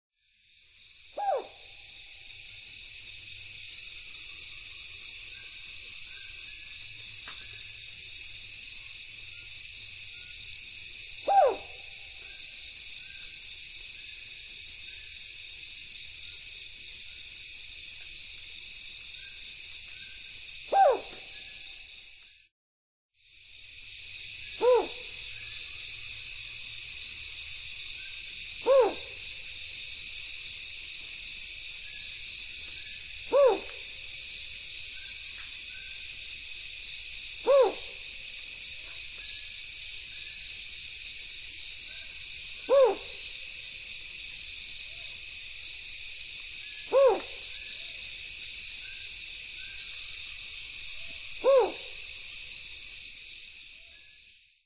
從清晨到黃昏的六段充滿鳥聲的自然錄音，搭配上27種鳥聲，最後以夜晚的貓頭鷹聲音做結尾，是最值得珍藏的自然聲音CD。